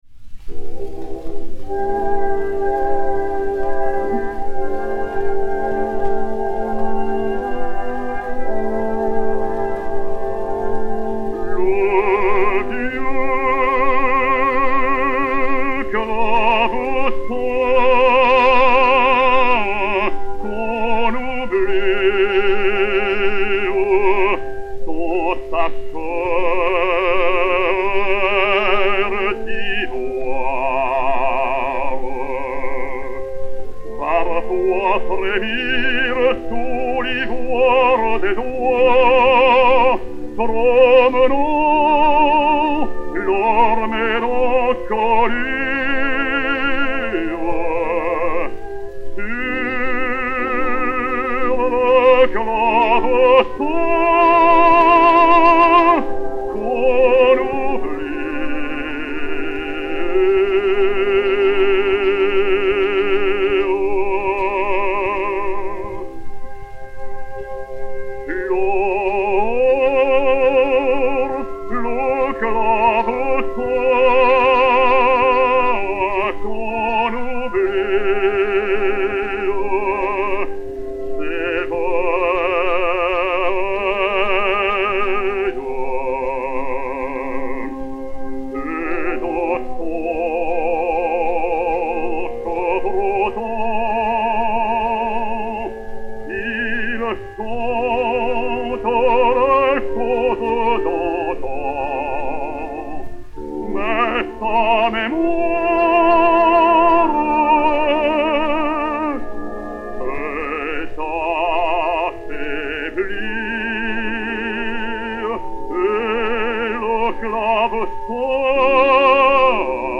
basse française
Mélodie (par.
Orchestre
Disque Pour Gramophone W 345, mat. 3479c (032349), enr. à Paris le 26 juin 1919